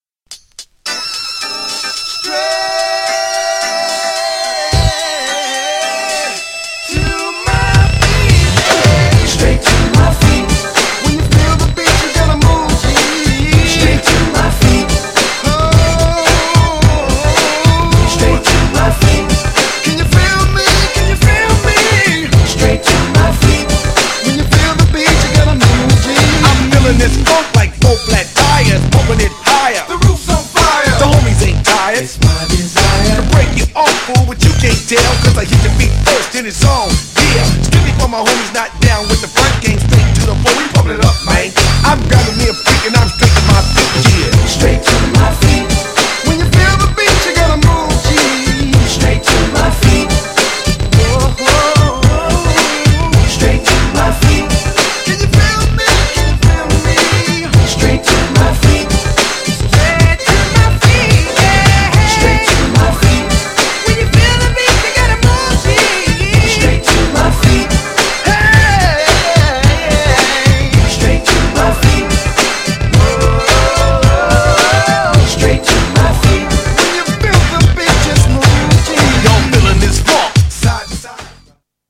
GENRE Hip Hop
BPM 106〜110BPM